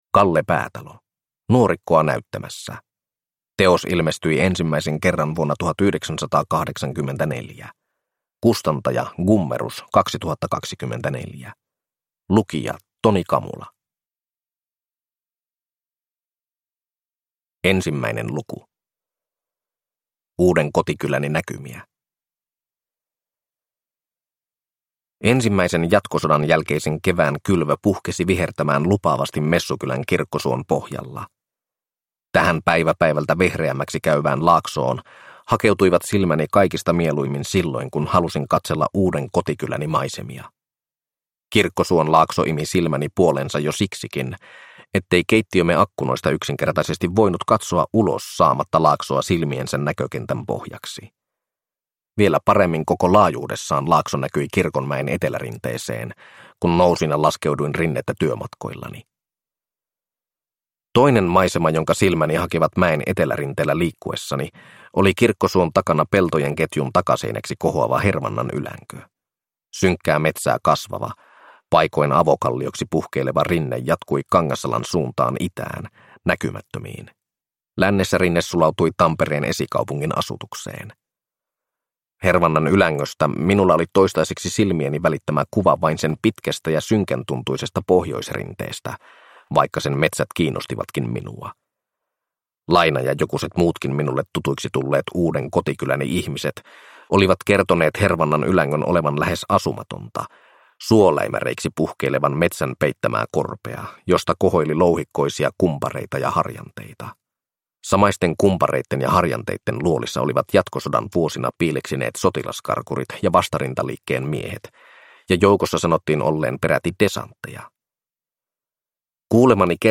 Nuorikkoa näyttämässä (ljudbok) av Kalle Päätalo